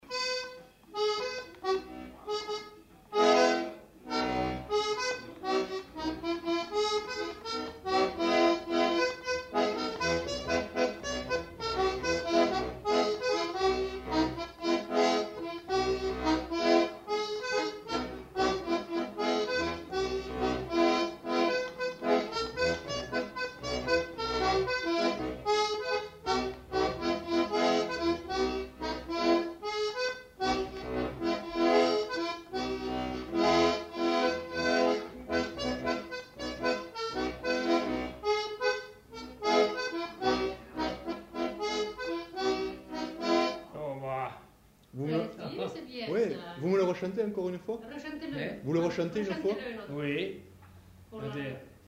Aire culturelle : Savès
Lieu : Pavie
Genre : morceau instrumental
Instrument de musique : accordéon diatonique
Danse : rondeau